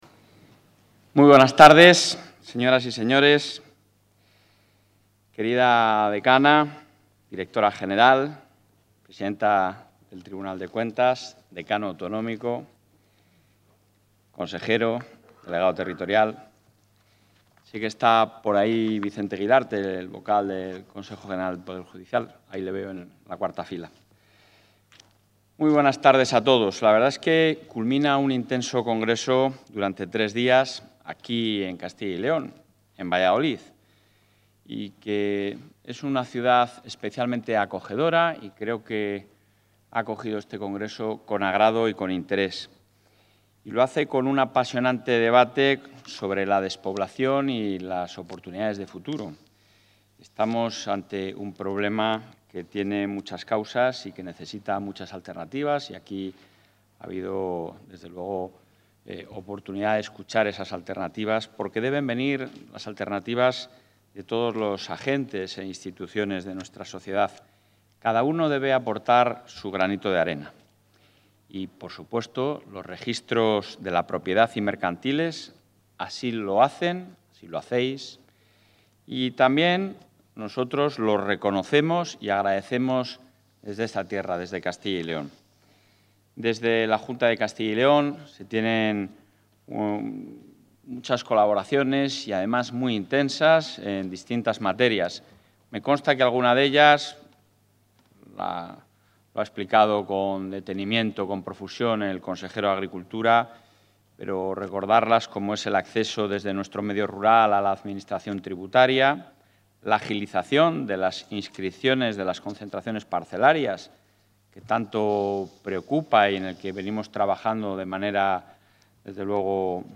Intervención del presidente de la Junta.
El presidente de la Junta valora, en la clausura del “Congreso Registral”, la contribución de la profesión a la sociedad